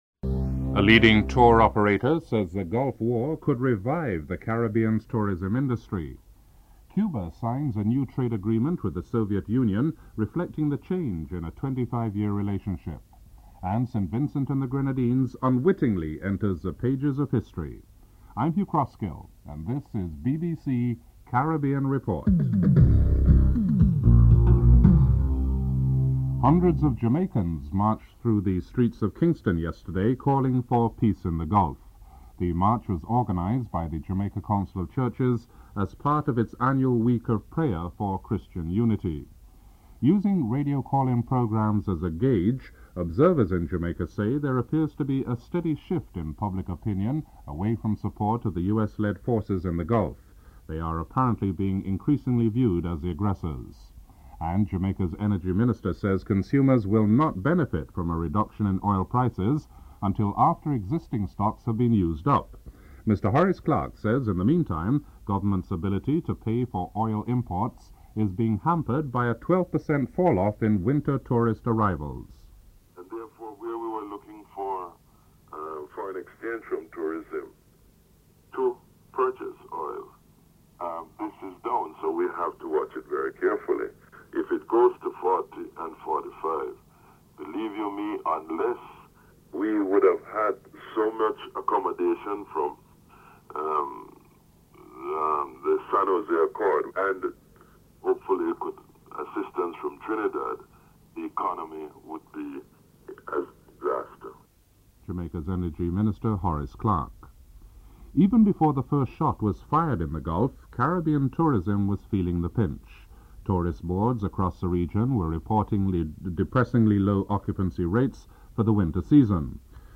Political leaders share their perspectives on the current political climate of Barbados and give their own predictions of the outcome of the elections (07:38-12:50)